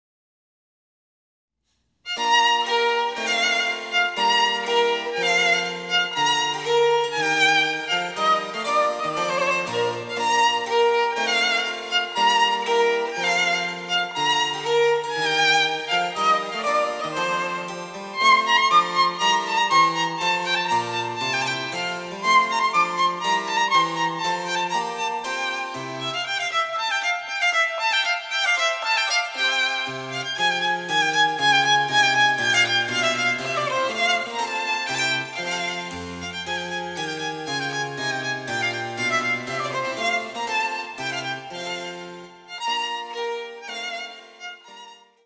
なんというなつかしく、爽やかで、希望に満ちた音楽であることか。
■ヴァイオリンによる演奏
チェンバロ（電子楽器）演奏